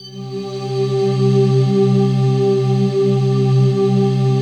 DM PAD2-44.wav